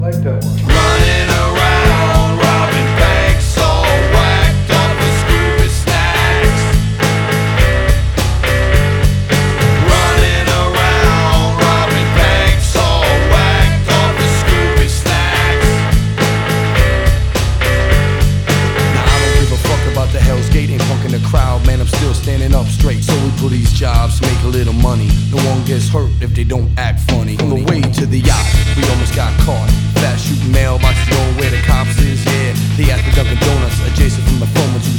Жанр: Хип-Хоп / Рэп / Рок / Альтернатива